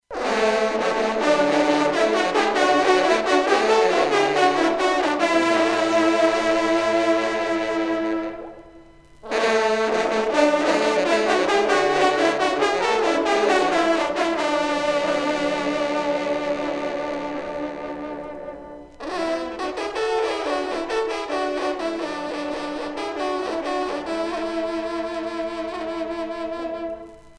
trompe - fanfare - circonstances
gestuel : danse
circonstance : vénerie